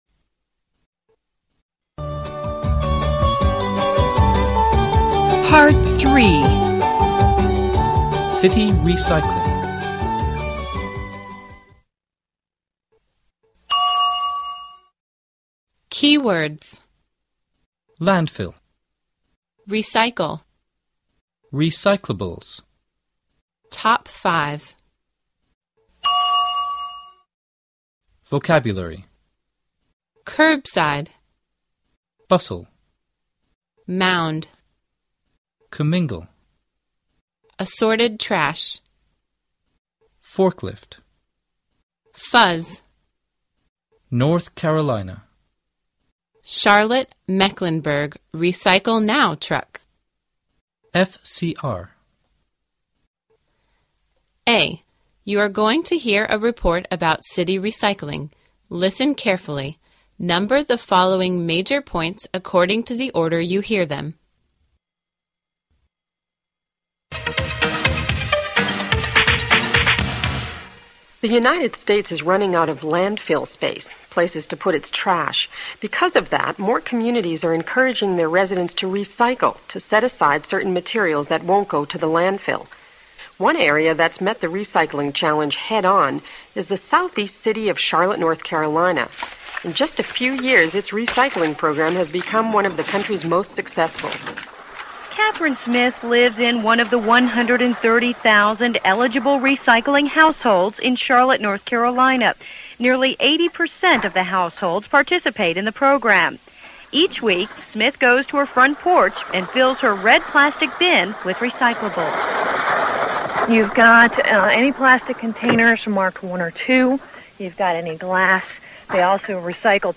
A. You're going to hear a report about city recycling.